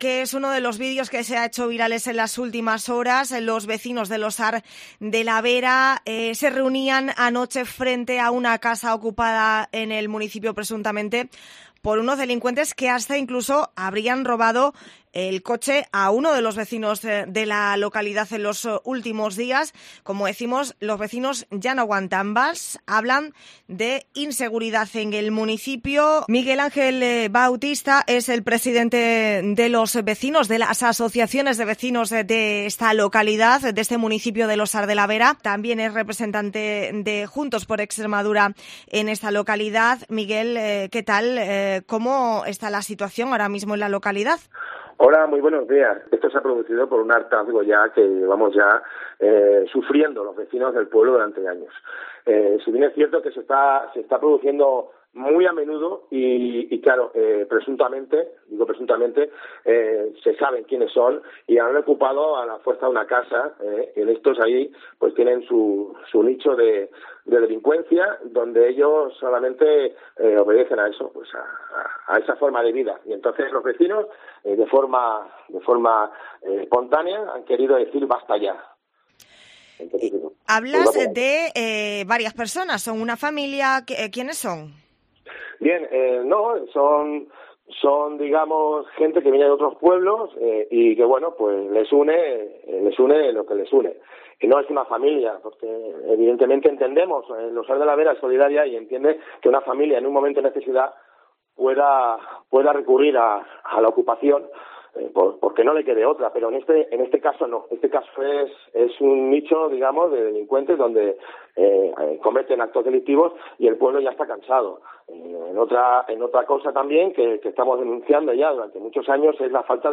Extremadura